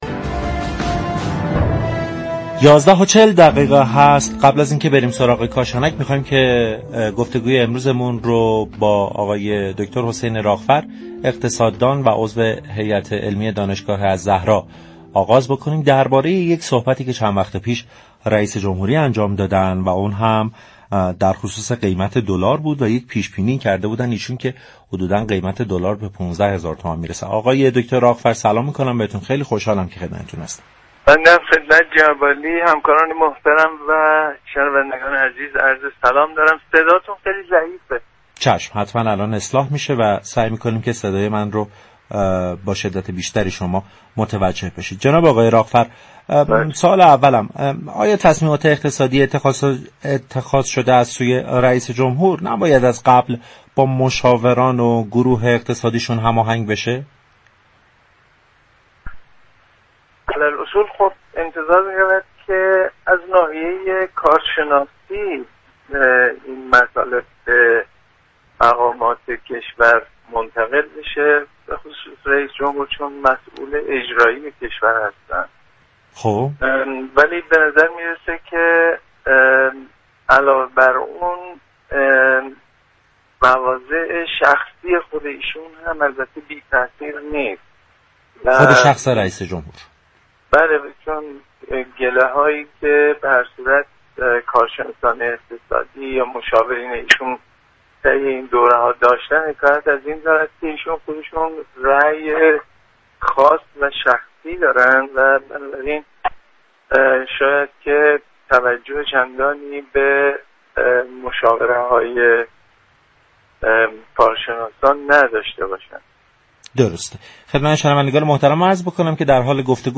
اقتصاددان كشورمان در گفتگو با برنامه بازار تهران